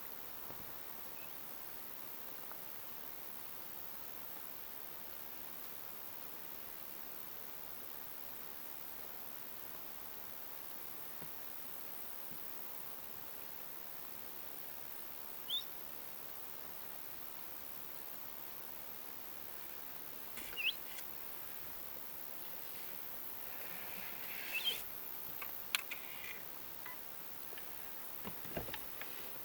Tämä ilmeisesti äänteli vähän pajulinnun kaltaisesti.
pajulintu ja bizt-tiltaltti
ilm_pajulintu_ja_bizt-tiltaltti.mp3